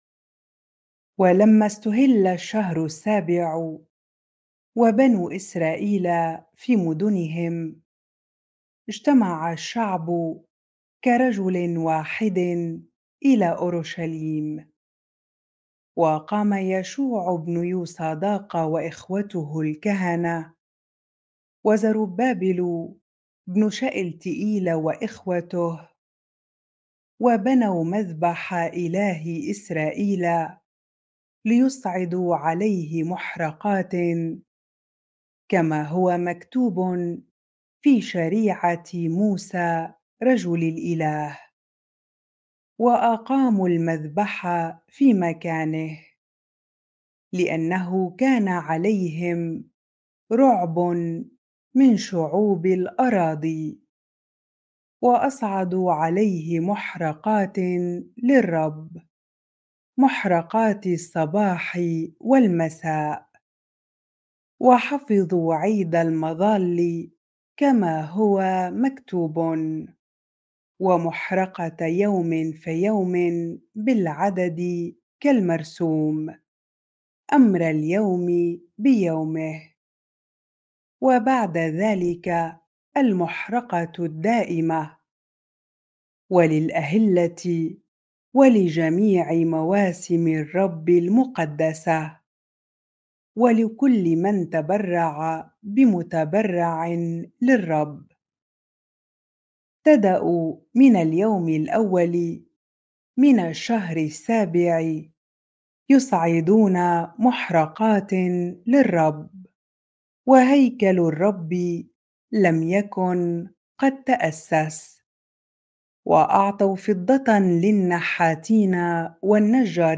bible-reading-Ezra 3 ar